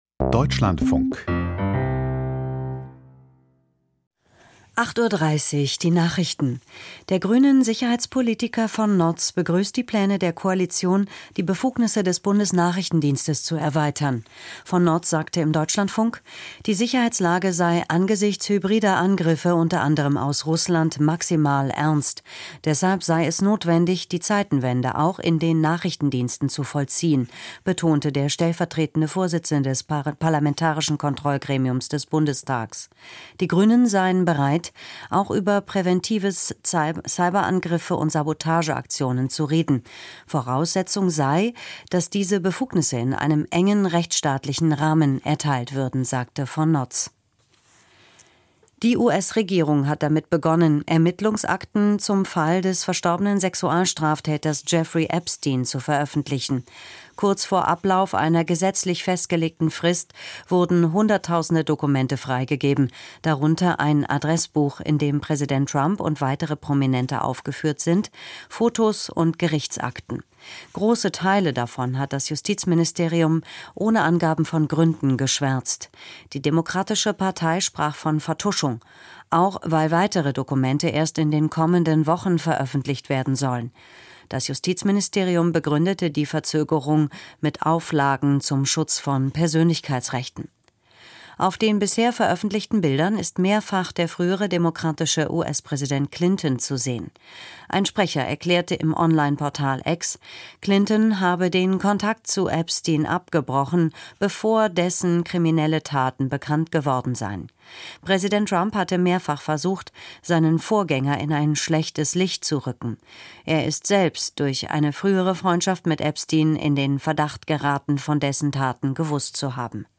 Die wichtigsten Nachrichten aus Deutschland und der Welt.
Aus der Deutschlandfunk-Nachrichtenredaktion.